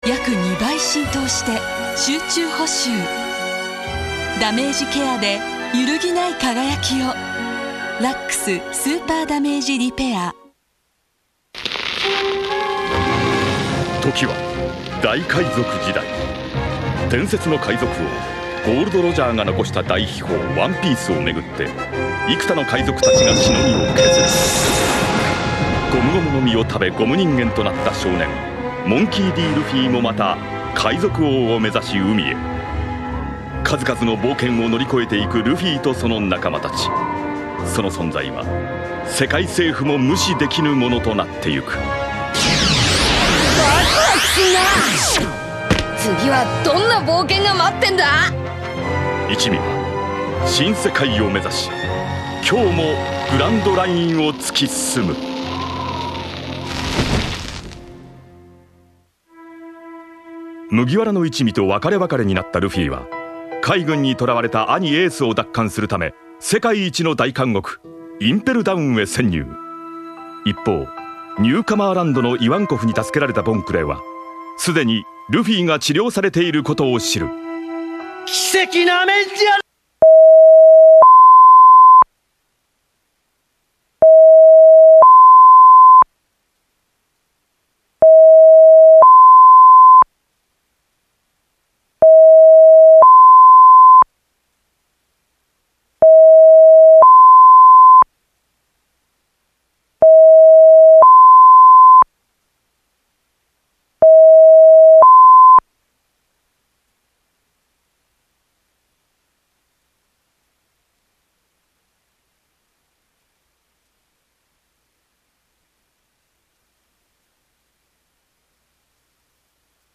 ちなみに途中の無音部分は長すぎるので省略してます。アニメは放送されているのに３分ぐらい無音。途中微妙に音が入るのもまた不気味さが増します。
ﾋﾟﾛﾋﾟﾛﾋﾟﾛﾋﾟﾛﾋﾟﾛﾋﾟﾛﾋﾟﾛﾋﾟﾛﾋﾟﾛﾋﾟﾛ………
あ、あと案の定見事なまでにキレイなsin波でした :-]